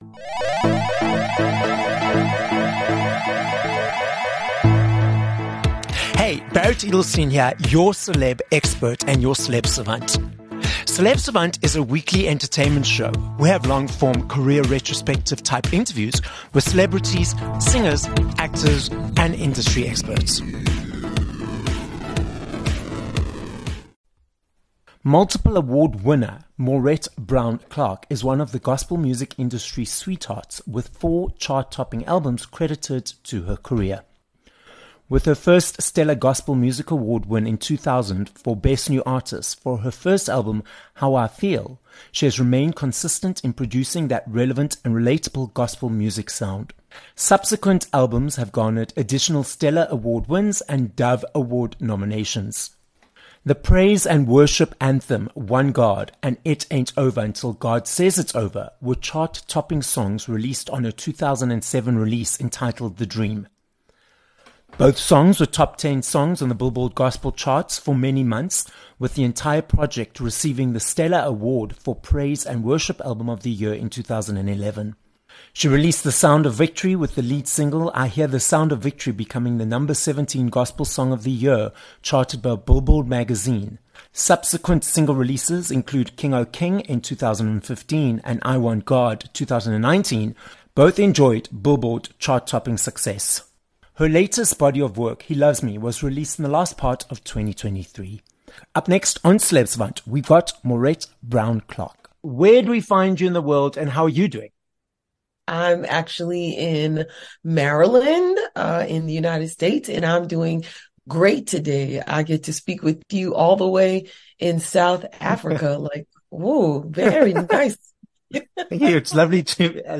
18 Jan Interview with Maurette Brown Clark
Chart-topping, Stellar award-winning and Dove award nominee, American gospel singer and songwriter - Maurette Brown Clark is the guest on this episode of Celeb Savant. Maurette explains that after university she had the car, job and boyfriend... but she had a hole in her heart, so she decided to follow her passion of music, which led to her award-winning successful career.